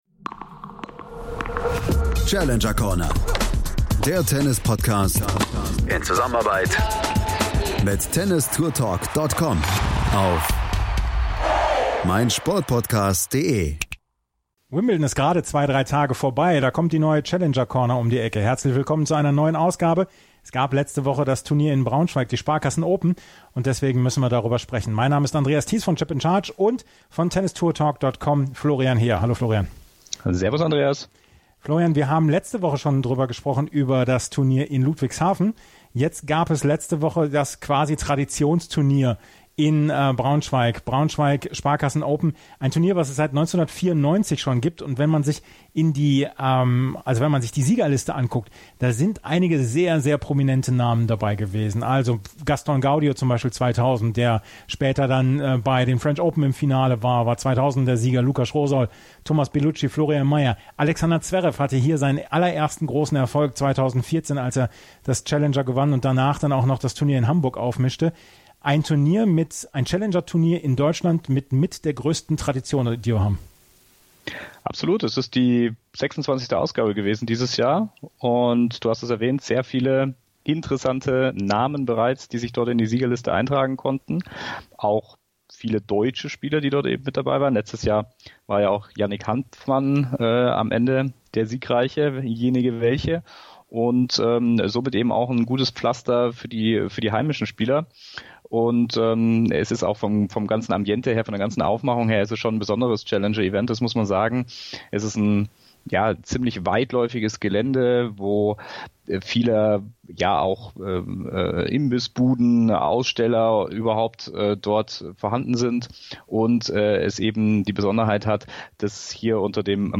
Auch Thiago Monteiro kann über seinen Turniersieg Auskunft geben.